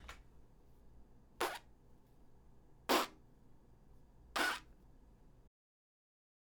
【歌唱スキル03】ヒューマンビートボックス①
次に、鼻呼吸はしたまま、膨らませた頬を手でつぶし、唇を振動させた音を出します。
※見本音声
h05_HBB_clap_teari.mp3